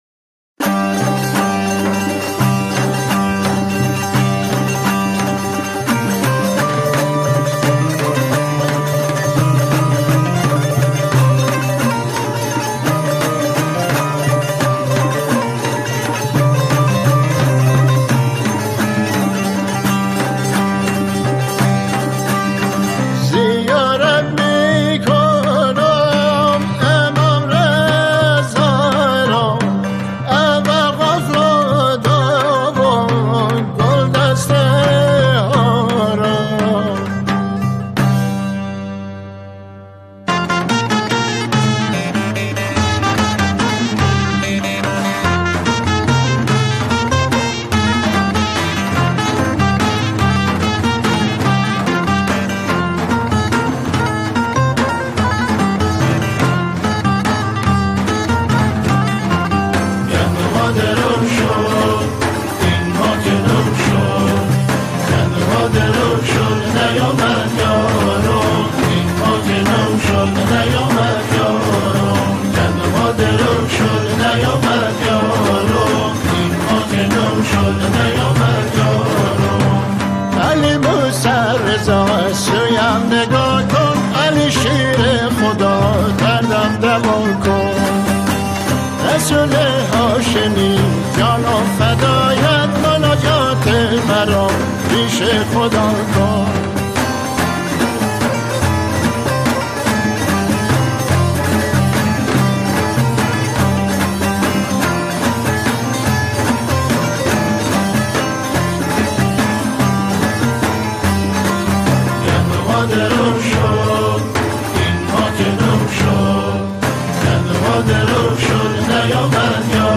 سرودهای امام زمان (عج)